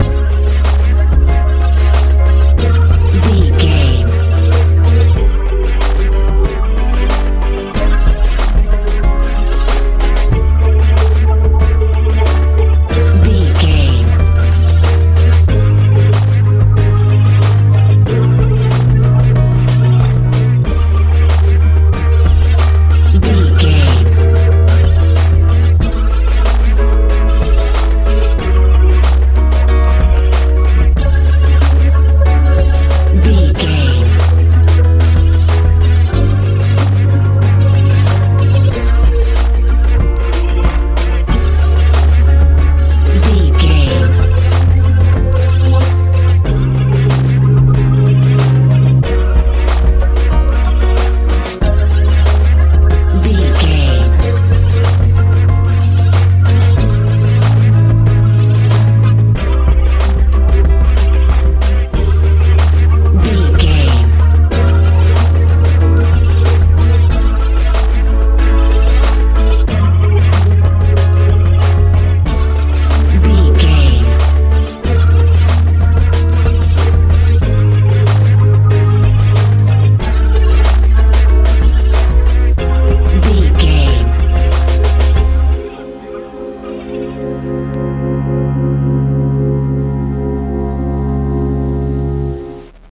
dance feel
Ionian/Major
magical
mystical
piano
synthesiser
bass guitar
drums
80s
strange